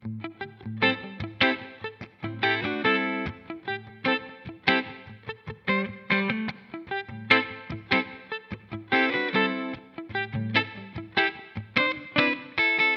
扩音吉他
描述：用FL Slayer制作的吉他环路。
标签： 100 bpm Hip Hop Loops Guitar Electric Loops 1.62 MB wav Key : Unknown
声道立体声